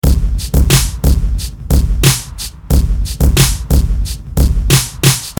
Earth, Water, fire, Wind Competition » Fire Crackles(No Room)
描述：A clip of fire in a metal stove crackling. Clip equalized slightly to take a hum out of the room, recorded indoors on a portadat with a shotgun microphone in the mountains of Colorado.
标签： crackles crackling embers fire flames
声道立体声